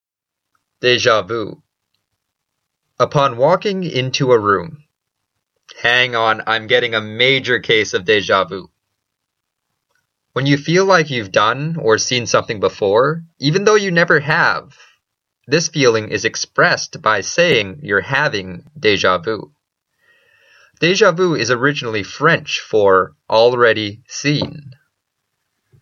英語ネイティブによる発音は下記のリンクをクリックしてください。
dejavu.mp3